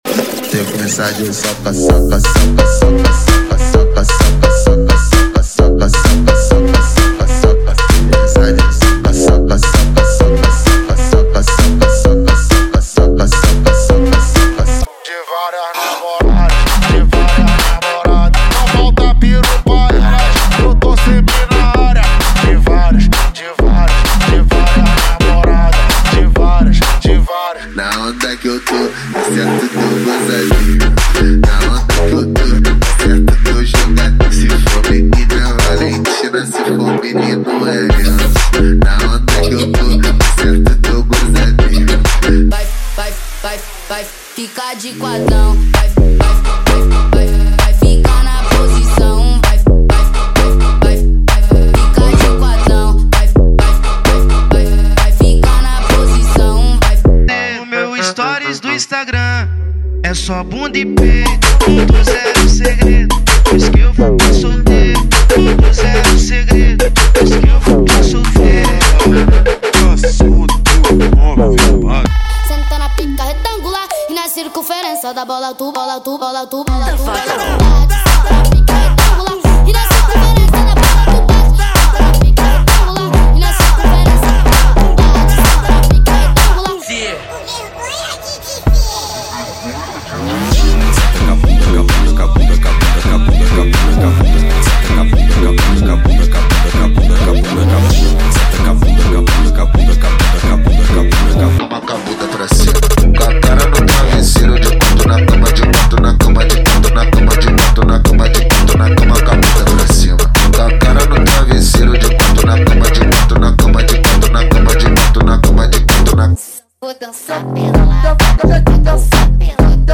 • Eletro Funk = 50 Músicas
• Sem Vinhetas
• Em Alta Qualidade